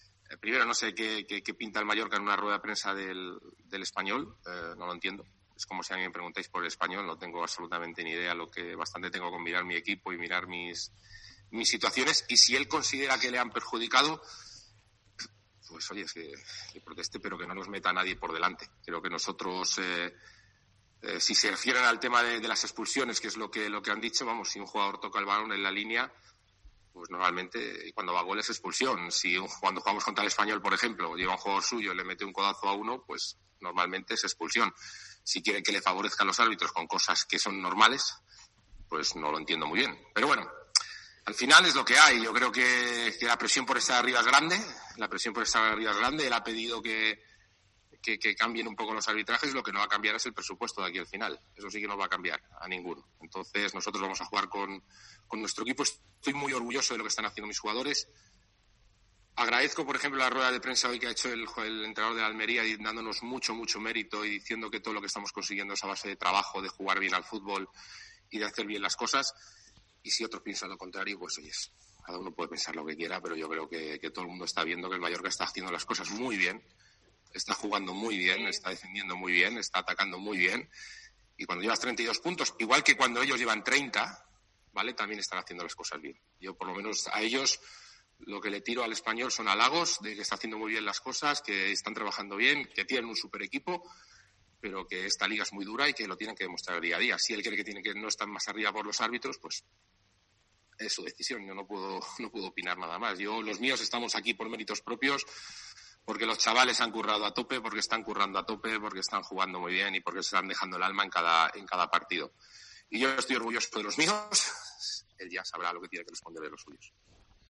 "No sé qué pinta el Mallorca en una rueda de prensa del Espanyol", ha expresado el técnico bermellón
Imagen: Rueda de prensa de Luis García. RCD Mallorca